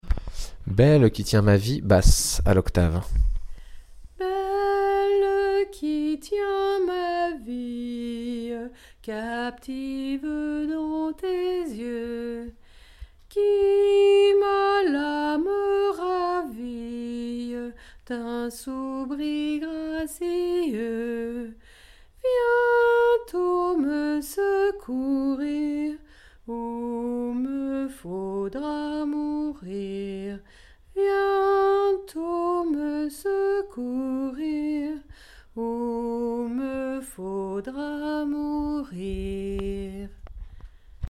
Pavane : danse de cour lente du XVIe siècle, exécutée par des couples en cortège
basse (à l’octave)
catégories : populaire, bal, polyphonie